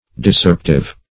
Search Result for " discerptive" : The Collaborative International Dictionary of English v.0.48: Discerptive \Dis*cerp"tive\, a. Tending to separate or disunite parts.
discerptive.mp3